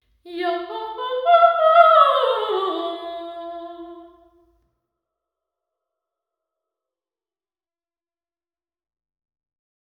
htcg_voice.wav